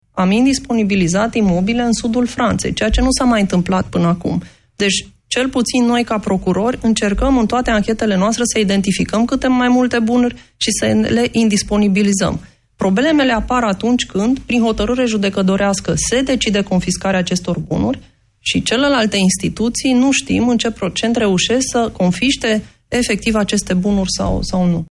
Invitată în emisiunea DRUM CU PRIORITATE, la Europa FM, şefa Direcţiei Anticorupţie a abordat problema confiscărilor.